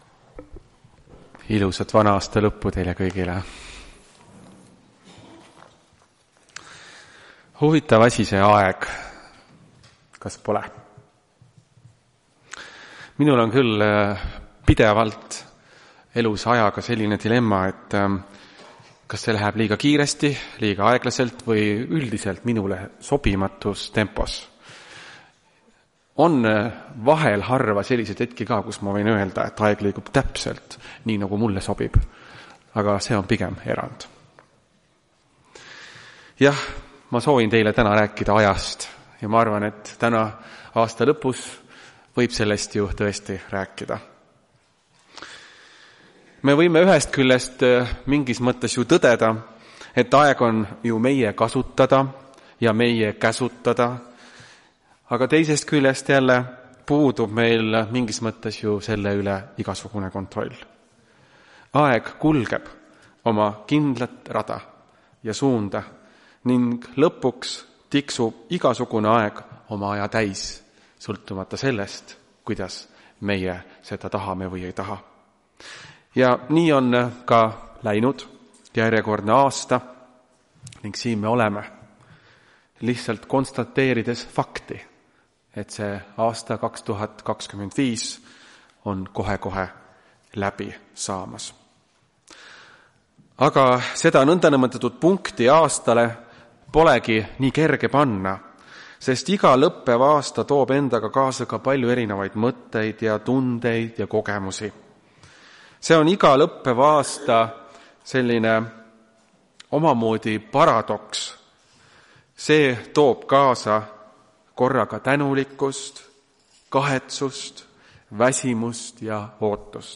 Tartu adventkoguduse 27.12.2025 teenistuse jutluse helisalvestis.
Jutlused